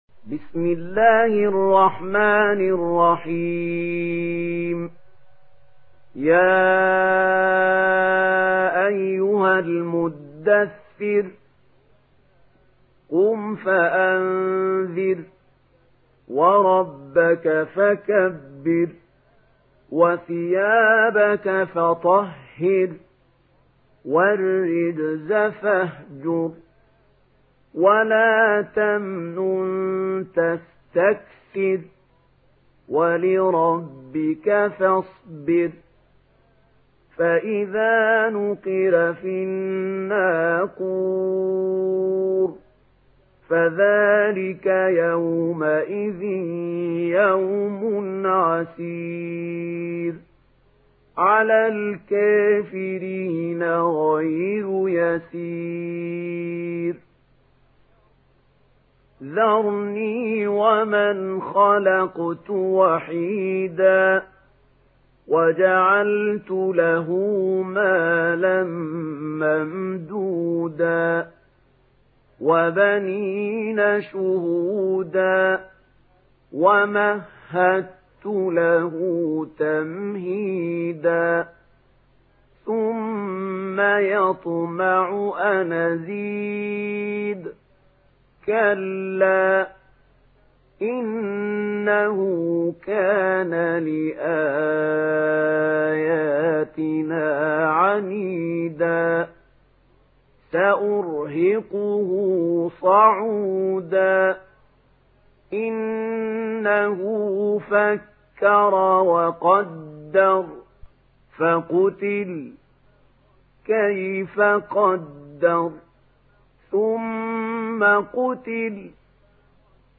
Surah Al-Muddathir MP3 in the Voice of Mahmoud Khalil Al-Hussary in Warsh Narration
Surah Al-Muddathir MP3 by Mahmoud Khalil Al-Hussary in Warsh An Nafi narration.